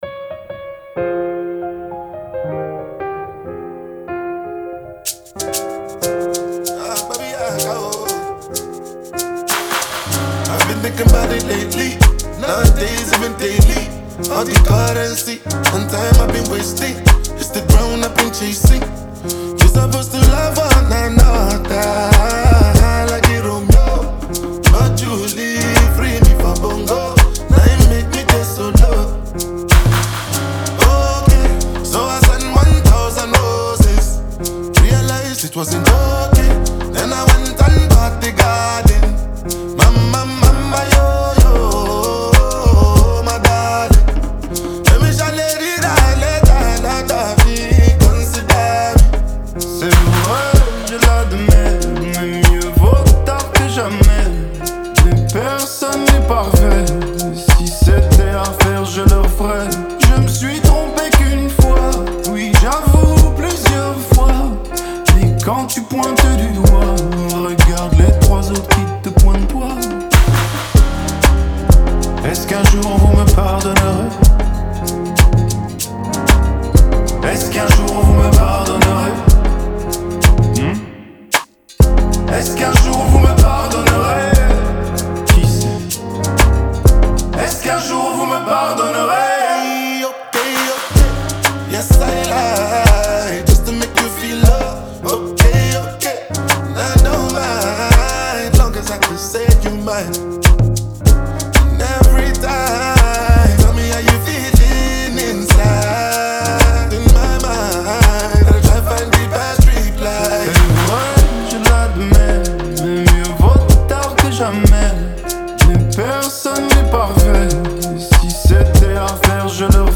European electronic influence
Afro-fusion style